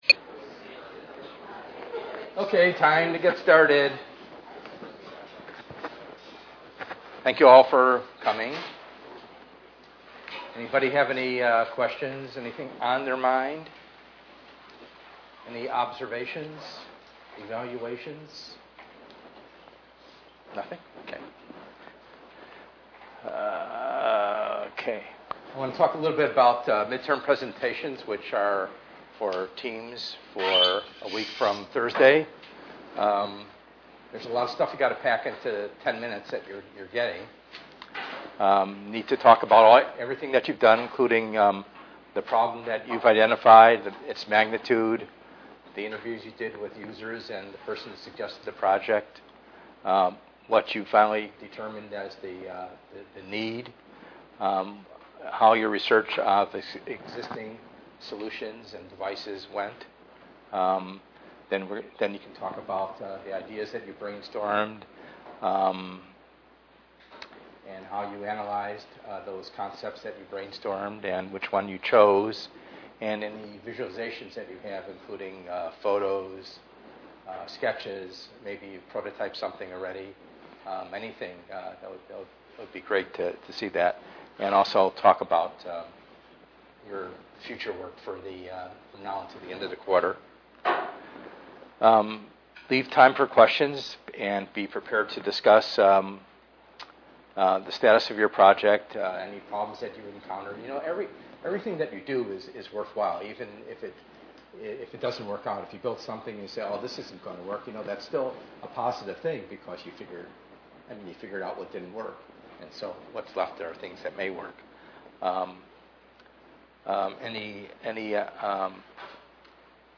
ENGR110/210: Perspectives in Assistive Technology - Lecture 05a